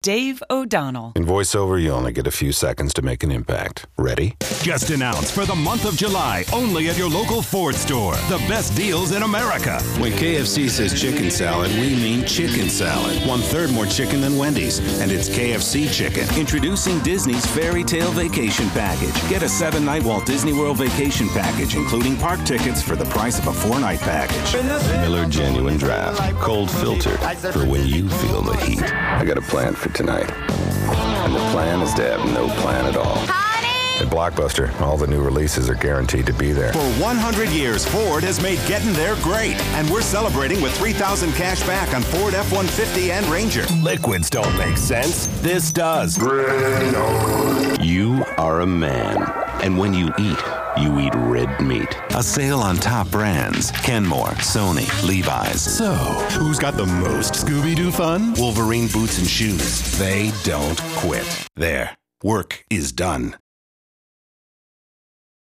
Voiceover : Promo : Men